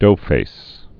(dōfās)